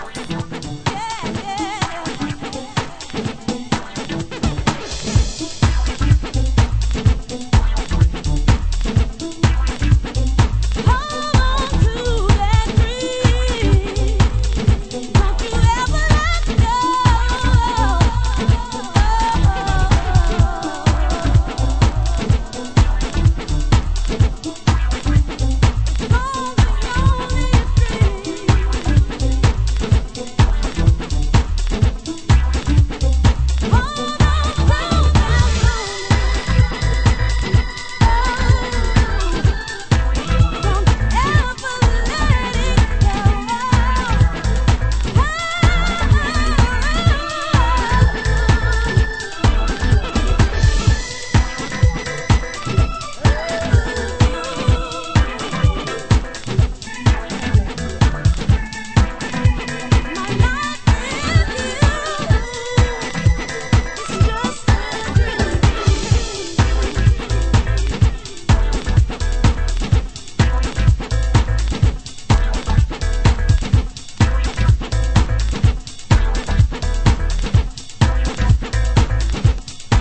HOUSE MUSIC
A2 (Bonus Beats)　 B1
(Broken Down Mix)　 B2 (Acappella) 　　 盤質：盤面良好ですが、ノイズあります。